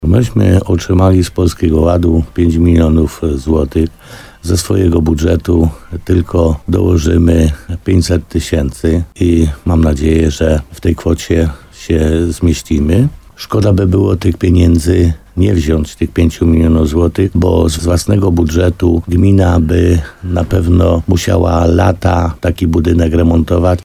W programie Słowo za słowo na antenie RDN Nowy Sącz Piotr Stach podkreślał też, że gmina wykorzystała okazję na sfinansowanie tej dużej inwestycji z rządowych pieniędzy.